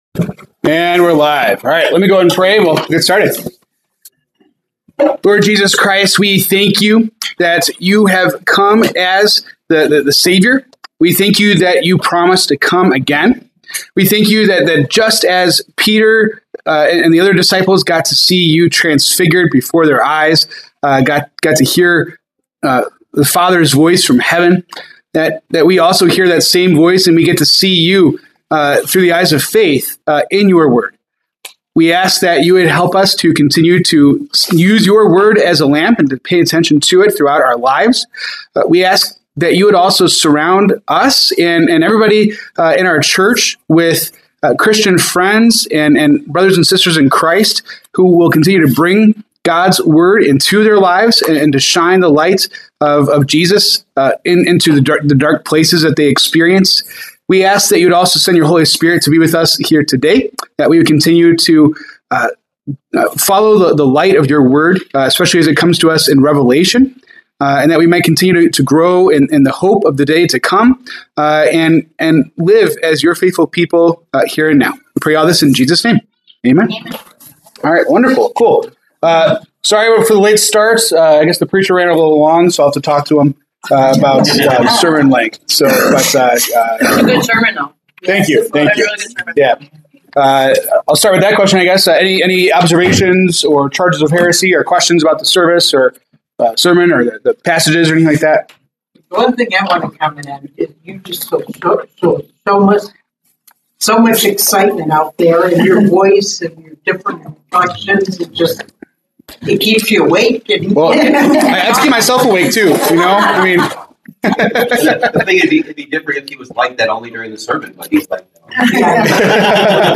February 15, 2026 Bible Study